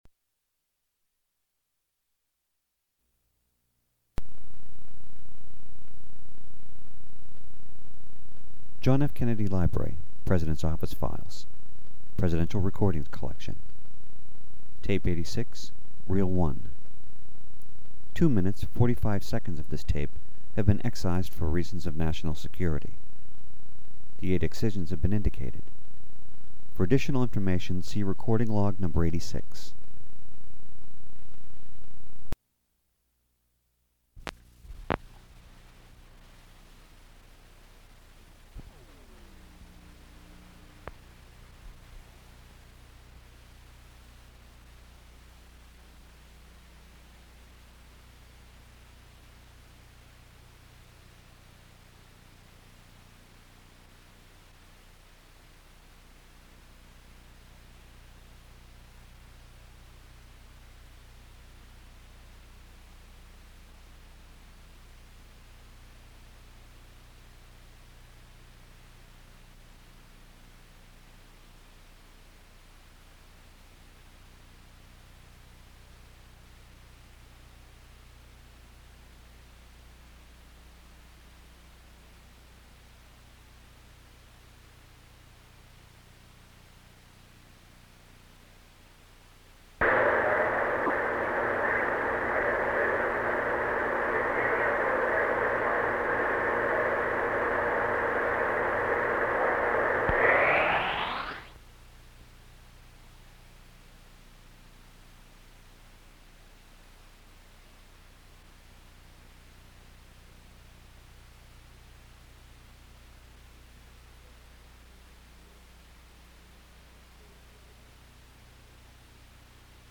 Sound recording of a National Security Council Meeting on India held on May 9, 1963.
President Kennedy speaks briefly, and then General Taylor’s point is affirmed strongly by Secretary of Defense Robert S. McNamara. Eight segments of the recording totaling 2 minutes and 43 seconds have been removed for reasons of national security.